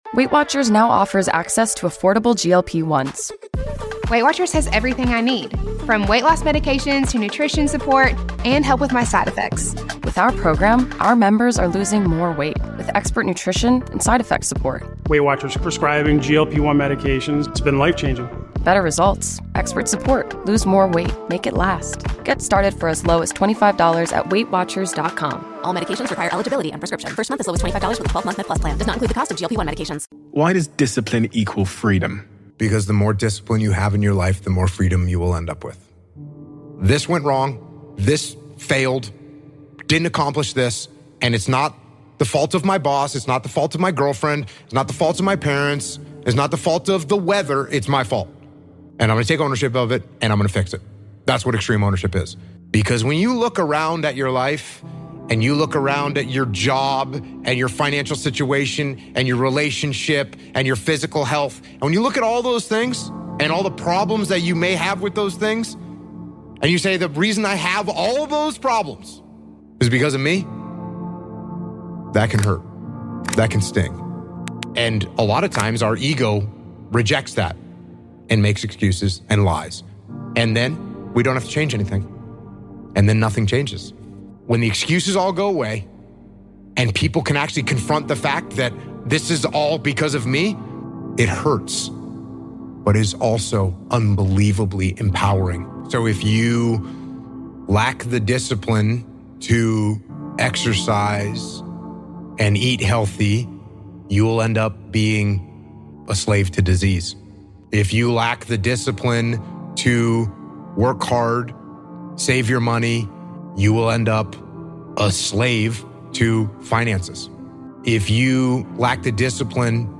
Jocko Willink - Why discipline equals freedom motivational speech
In this commanding episode, former Navy SEAL commander and leadership expert Jocko Willink delivers his signature no-excuses truth: discipline equals freedom. Drawing from his combat-proven experience leading SEAL teams in Iraq and his decades of extreme ownership philosophy, Jocko explains why discipline isn’t punishment or restriction—it’s the only real path to total freedom in every area of life.